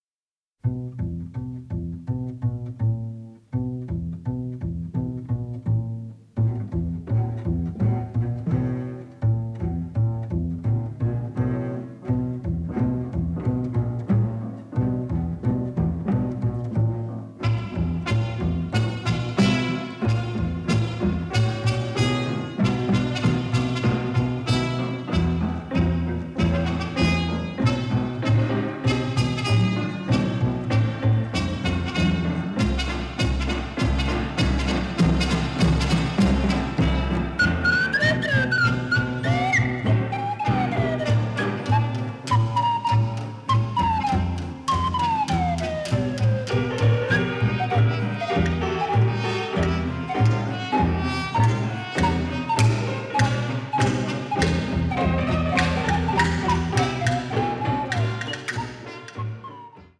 avantgarde score
It had less music, but it was all in stereo.